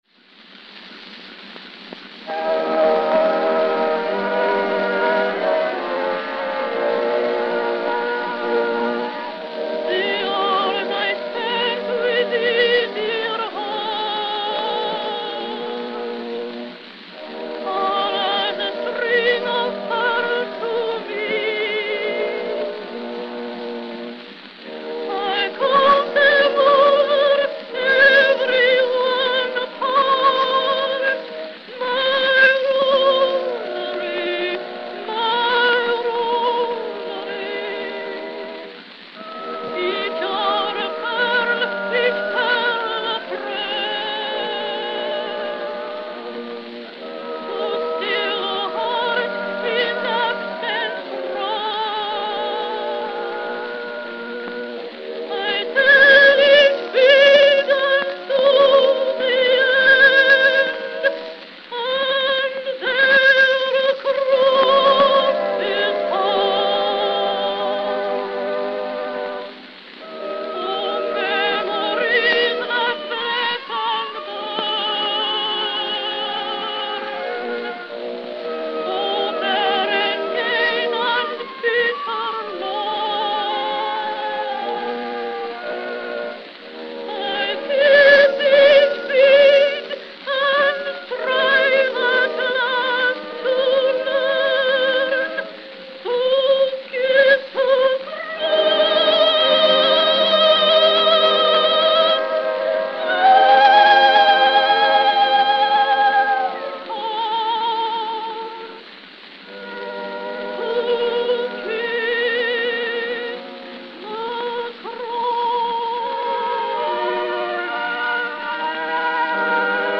Note: Worn.